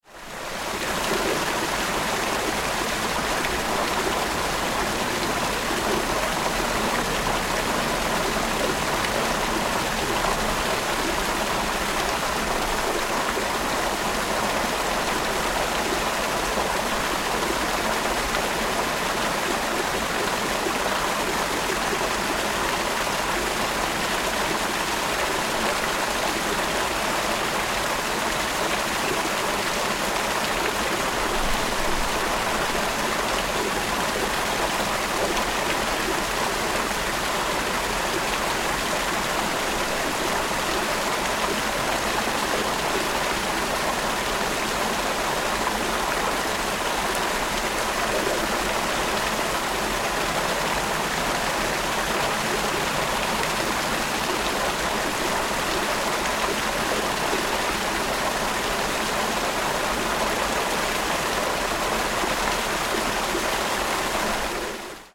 Звуки аквапарка
Шум водяных брызг в аквапарке